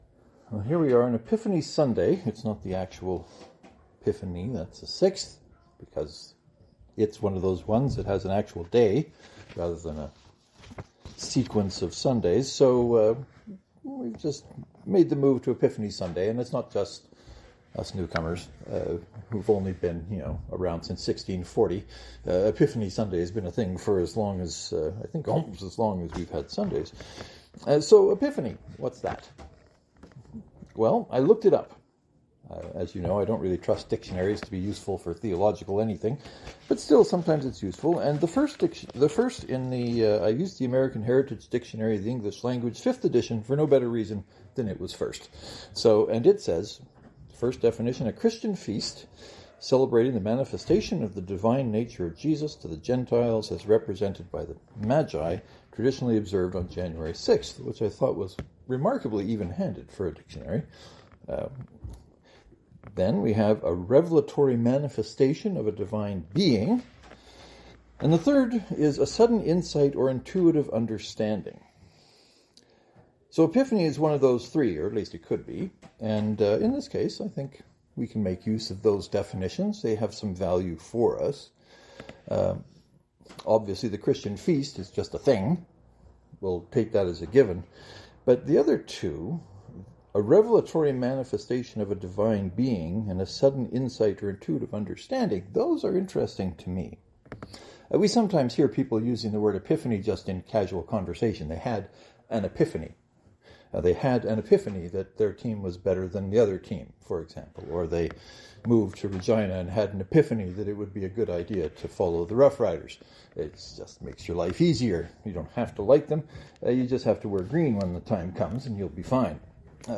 This sermon has some tricky bits in it, or at least some bits that might be tricky.